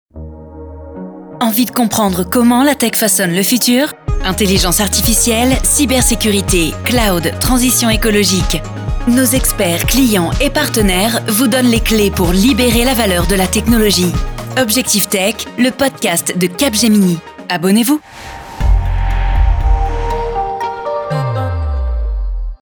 Spot diffusé sur Spotify pour promouvoir le podcast de Capgemini « Objectif Tech »